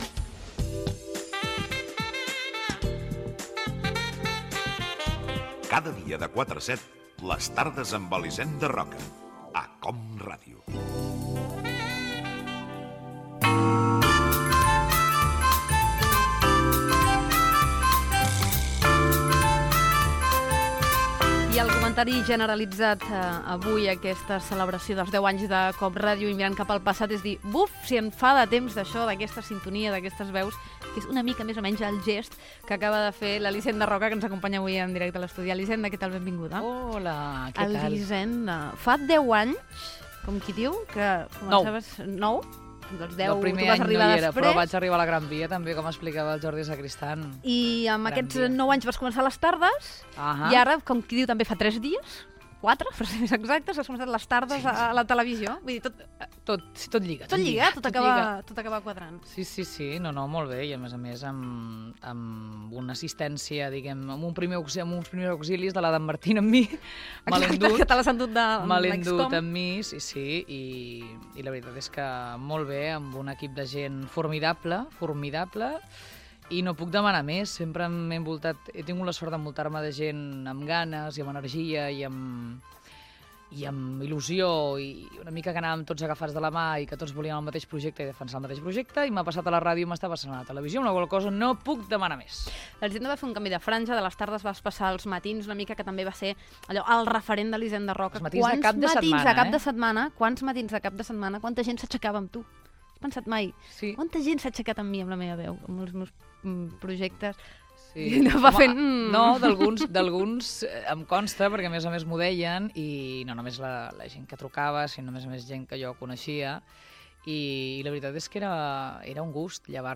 Amb motiu dels 10 anys de COM Ràdio. entrevista a Elisenda Roca que va presentar "Les tardes amb Elisenda Roca" i "Dies de ràdio" a COM Ràdio.
Entreteniment
FM